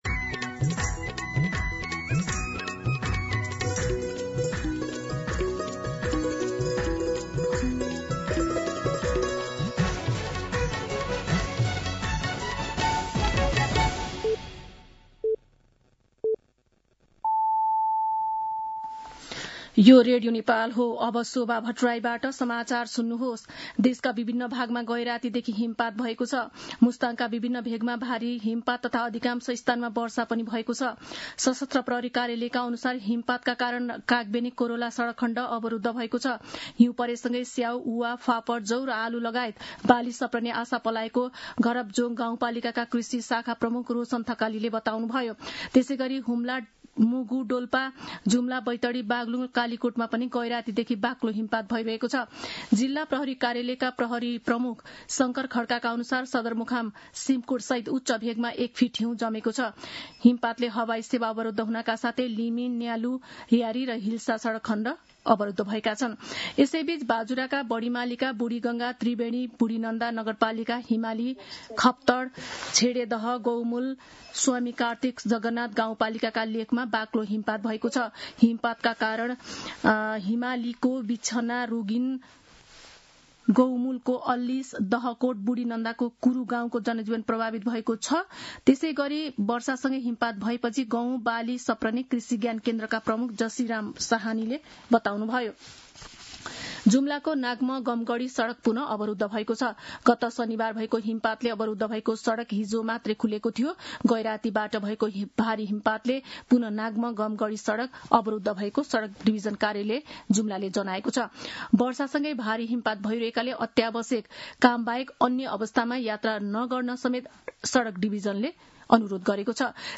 मध्यान्ह १२ बजेको नेपाली समाचार : १४ माघ , २०८२